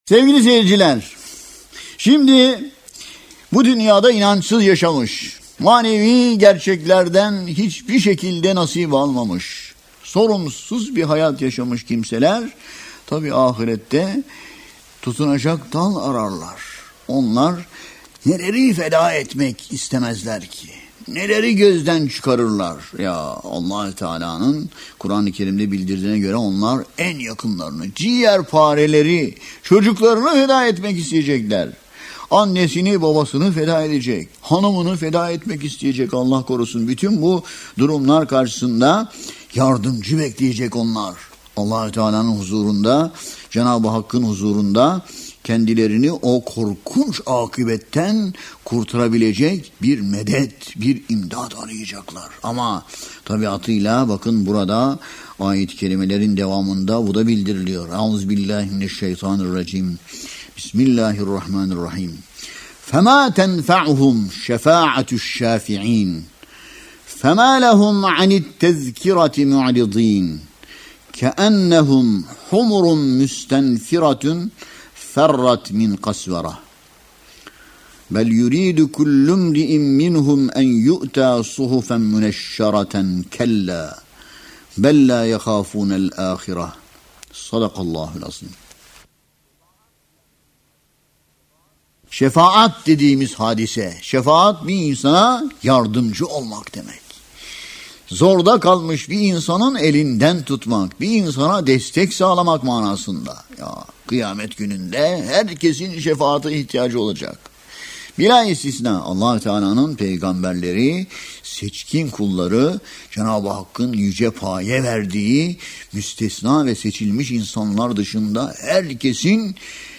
Tefsir